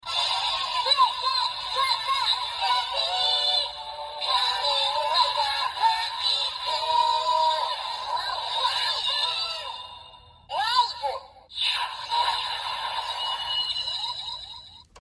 Live饱藏音效.MP3